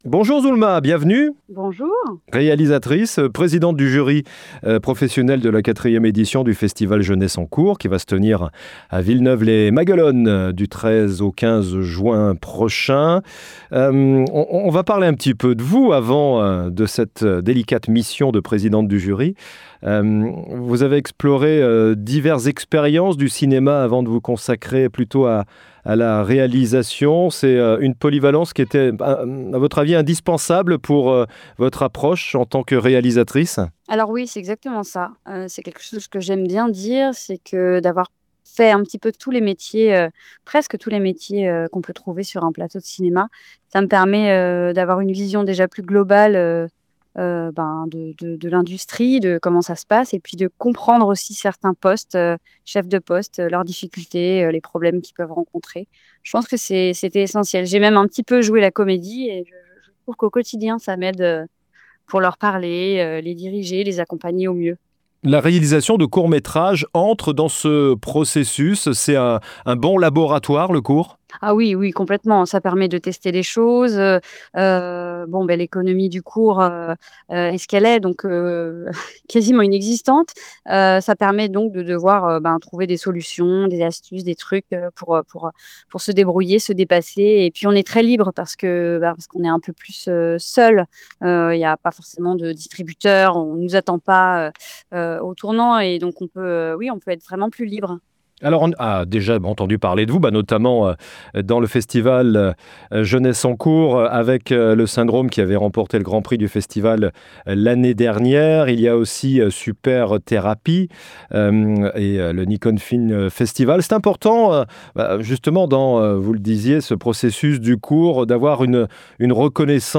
Réalisatrice au profil atypique qui nous a accordé une interview à un mois de l'évènement.